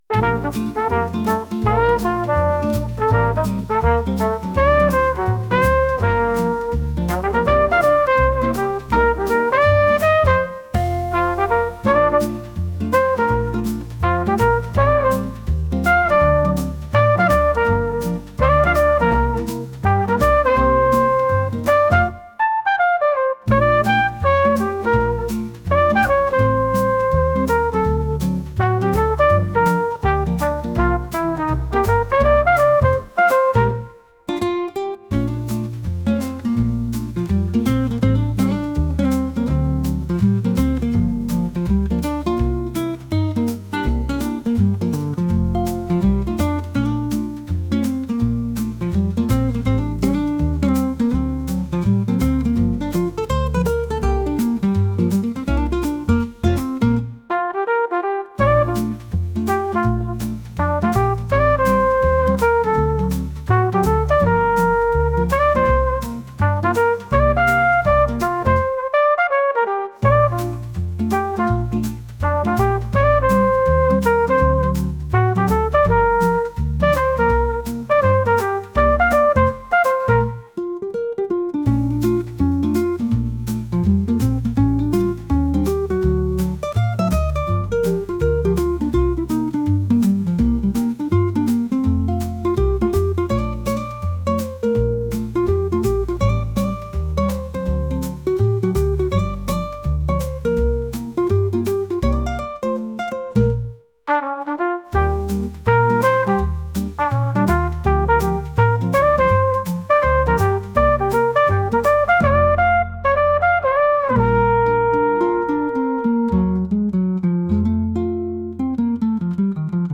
服屋で流れているようなトランペット曲です。